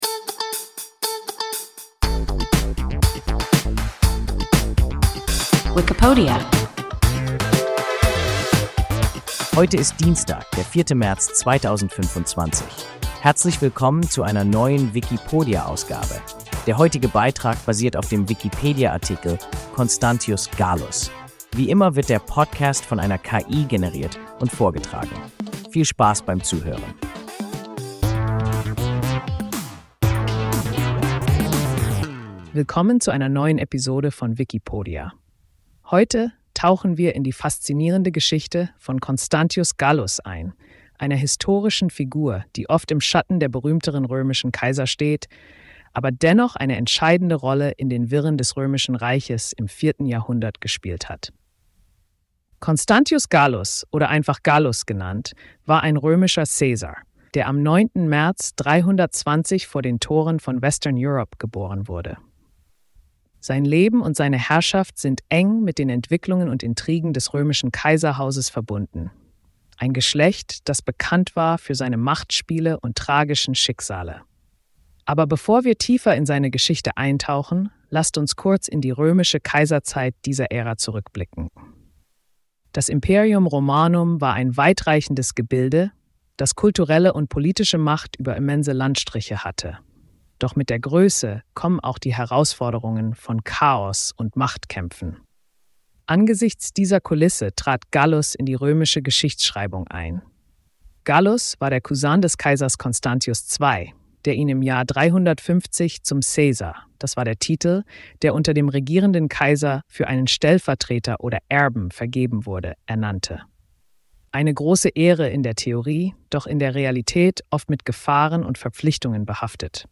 Constantius Gallus – WIKIPODIA – ein KI Podcast